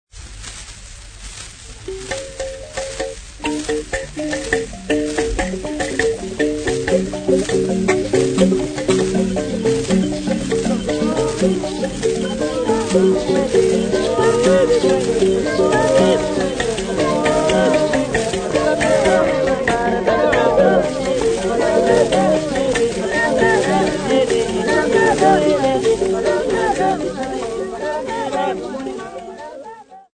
Folk Music
Field recordings
sound recording-musical
Indigenous music